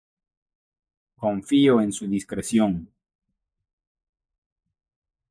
dis‧cre‧ción
/diskɾeˈθjon/